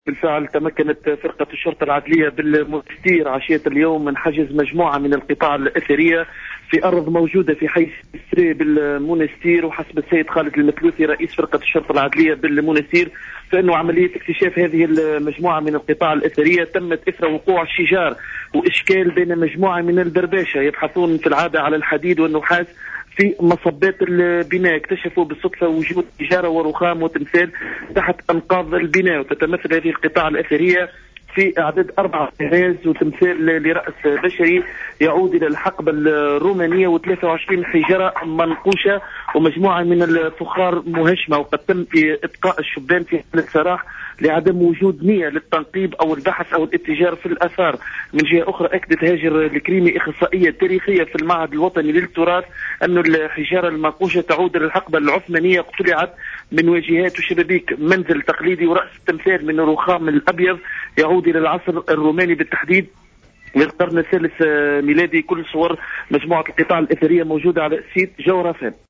التفاصيل مع مراسلنا